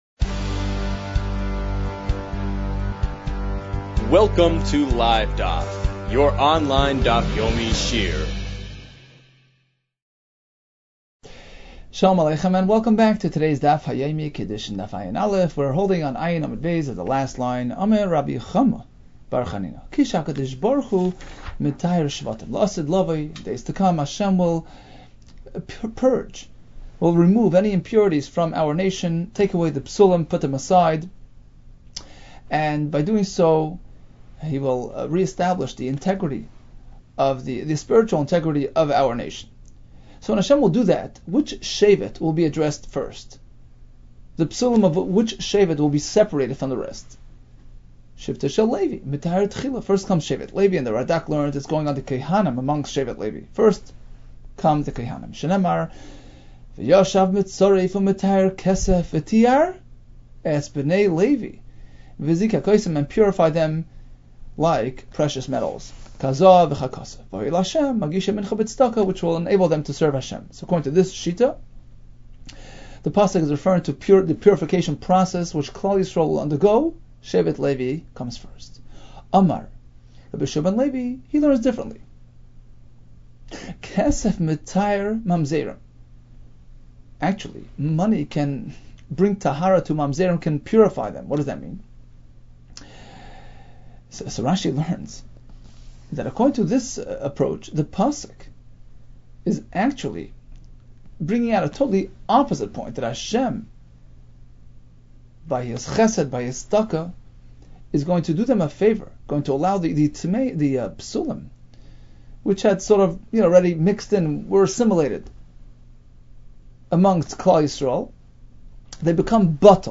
Kiddushin 71 - קידושין עא | Daf Yomi Online Shiur | Livedaf